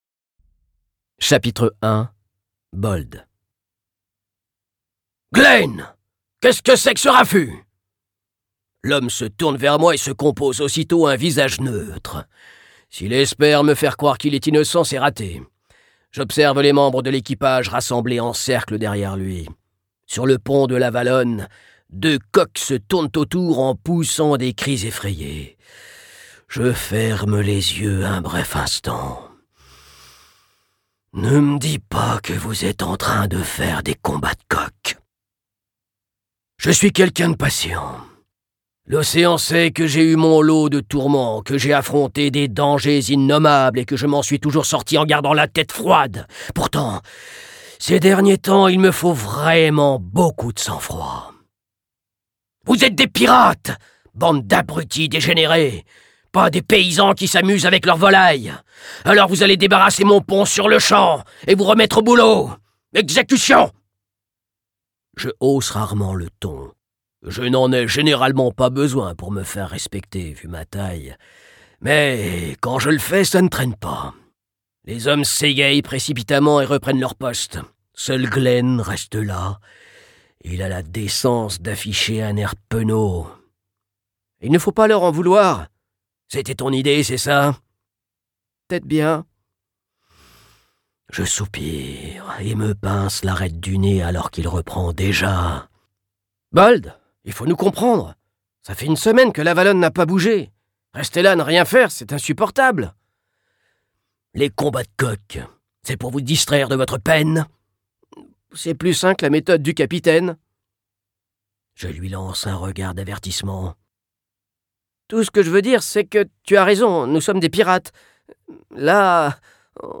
je découvre un extrait